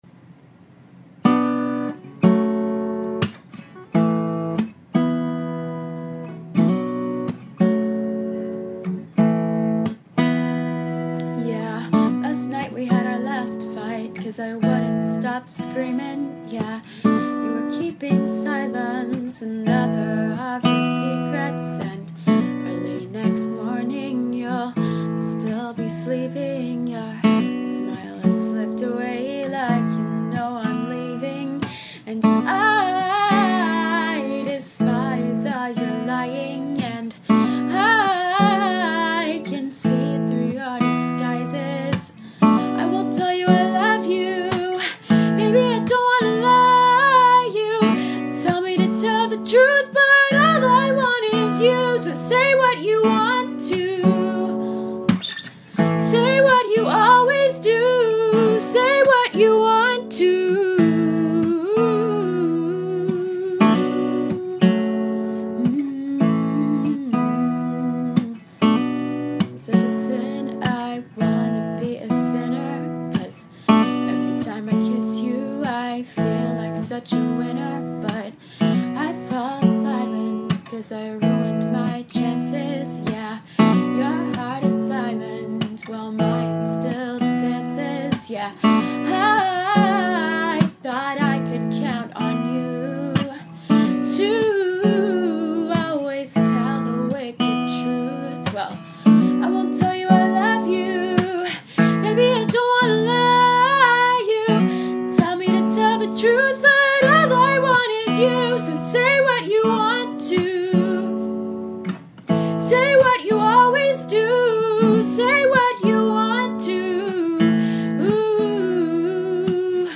Here is a live one take wonder!